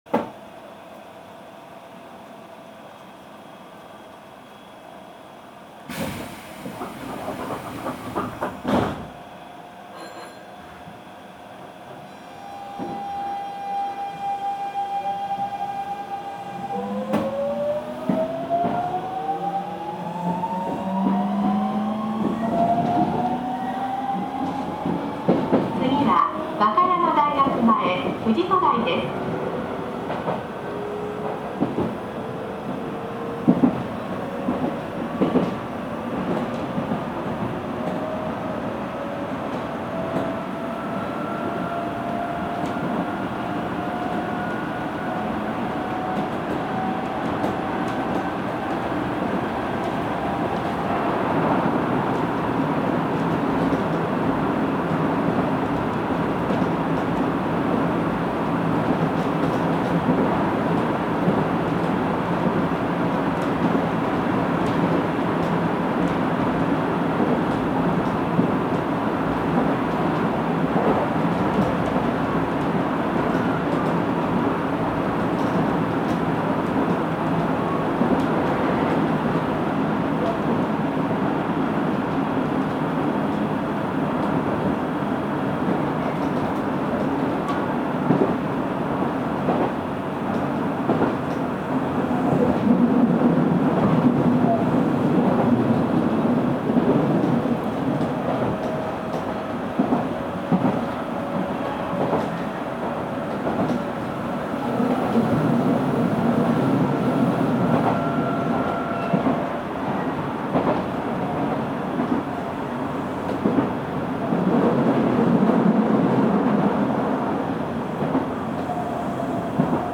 走行機器は1～5次車ではGTO素子によるVVVFインバータ制御ですが、6次車のみ2レベルIGBT素子によるVVVFインバータ制御となっています。
走行音
GTO車
録音区間：紀ノ川～和歌山大学前(お持ち帰り)